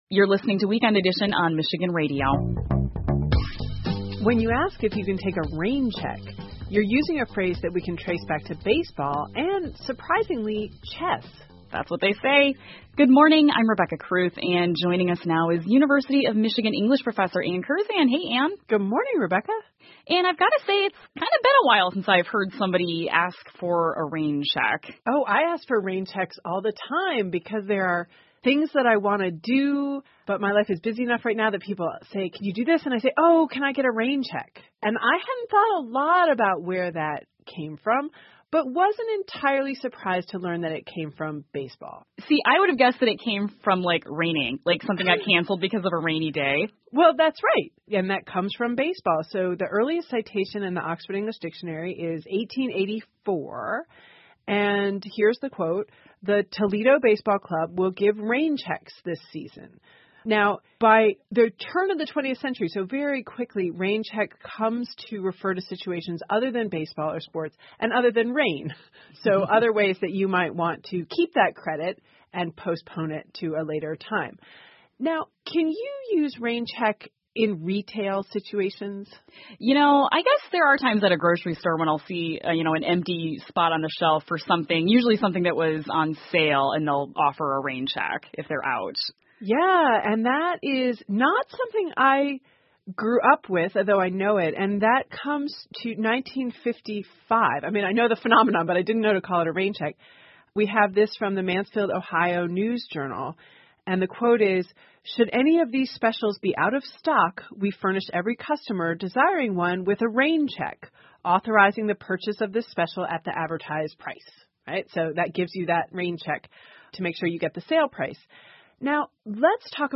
密歇根新闻广播 "Rain check"的用法 听力文件下载—在线英语听力室